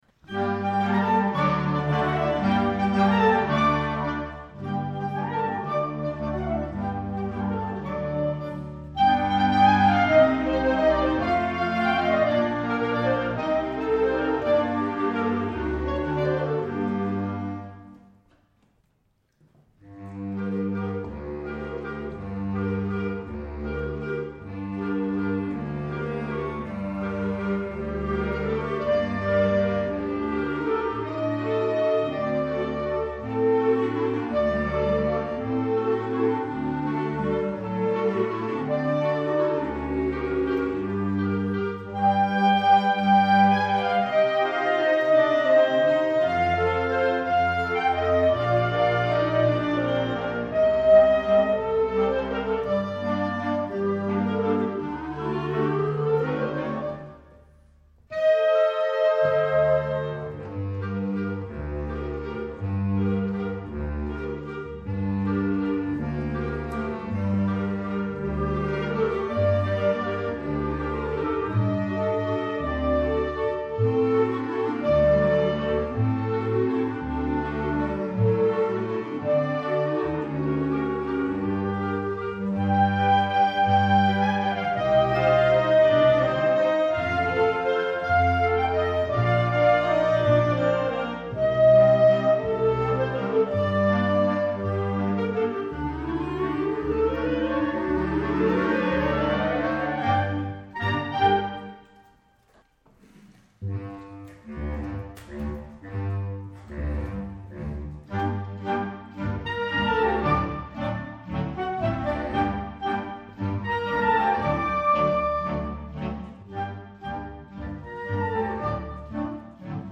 Ce fut un beau spectacle je trouve !
d'écrire un spectacle original pour ensemble de clarinette
mercredi 18 avril 2007 : concert à 20h30... entrée gratuite